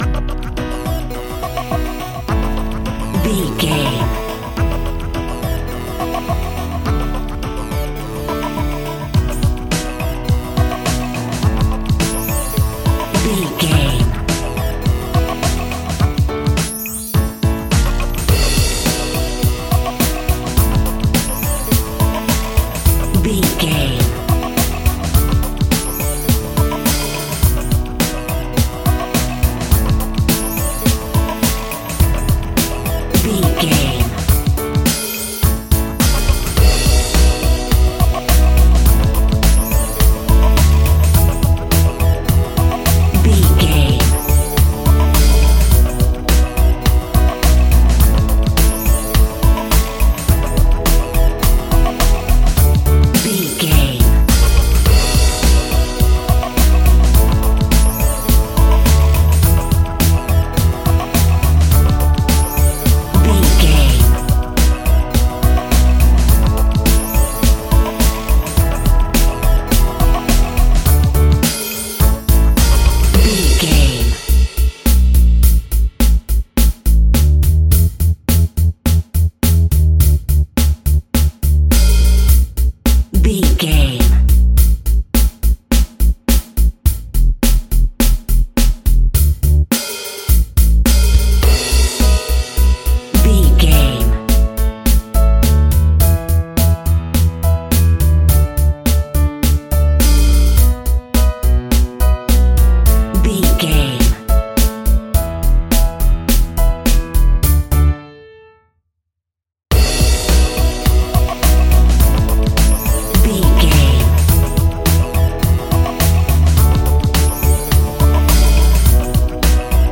Romantic Pop Rock Comedy Music.
Ionian/Major
fun
energetic
uplifting
drums
bass guitar
electric guitar
synthesizers